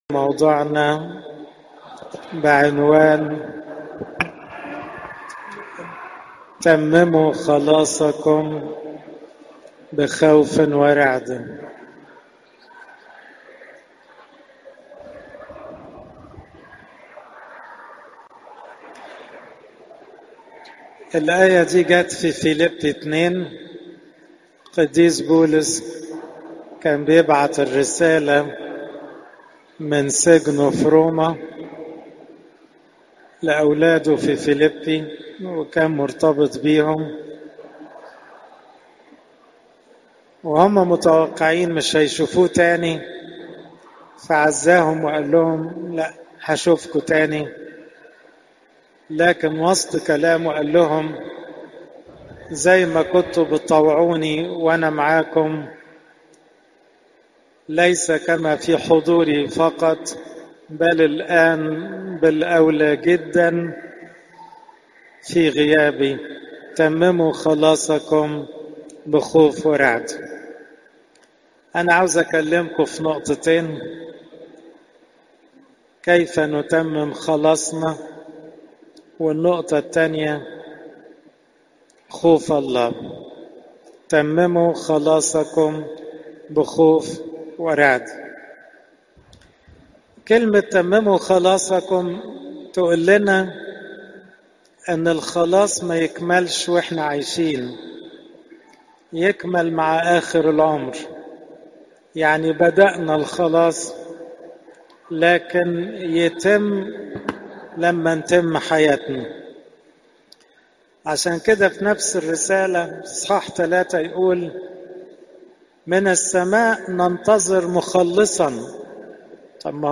عظات نهضة صوم العذراء